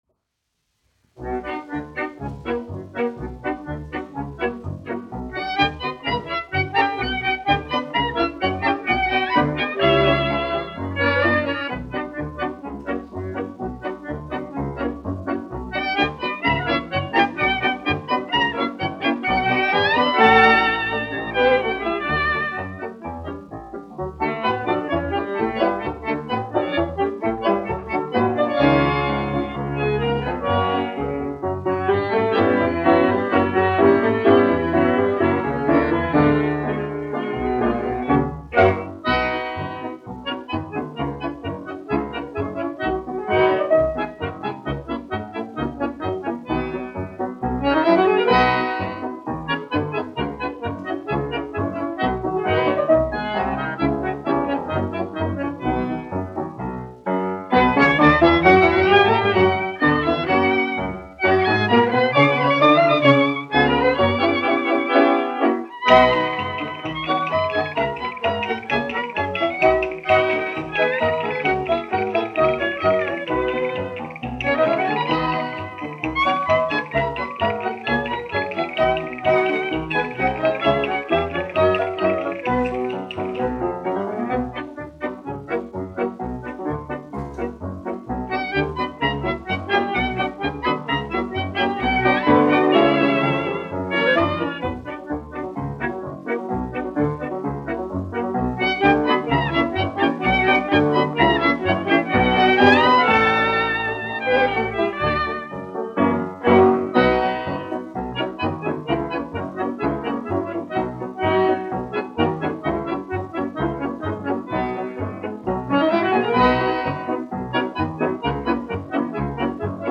1 skpl. : analogs, 78 apgr/min, mono ; 25 cm
Deju orķestra mūzika
Populārā instrumentālā mūzika
Skaņuplate